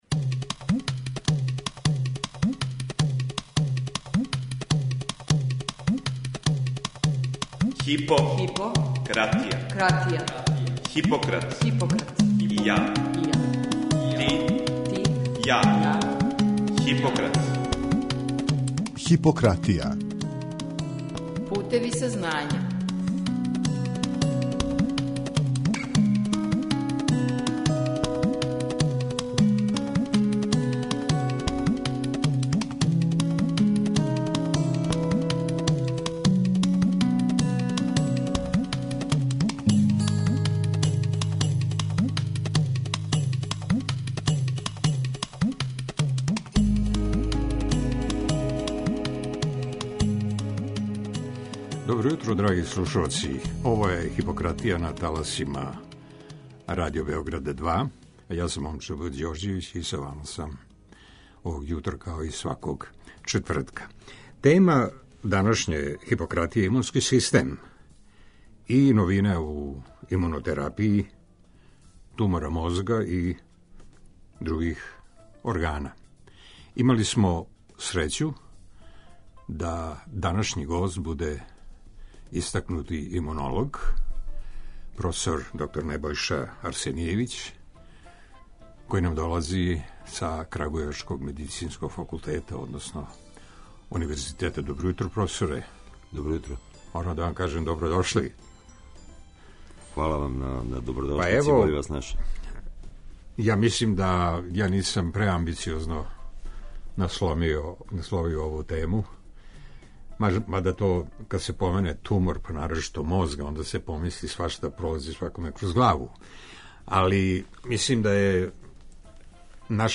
Гост данашње Хипократије је имунолог